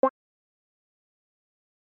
Vaikka ääni olisi näinkin hento?